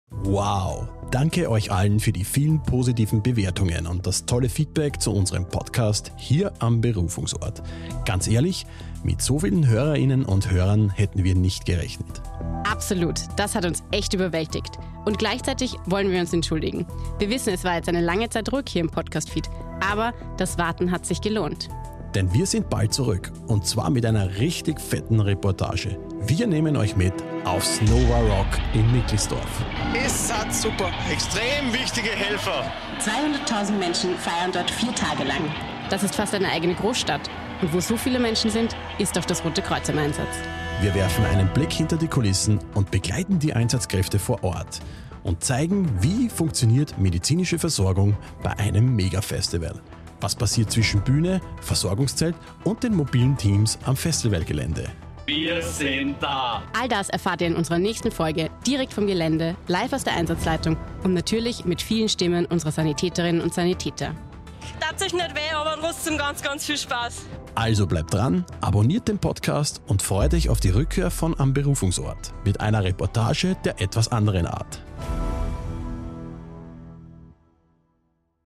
Die Reportage vom Einsatz für Menschen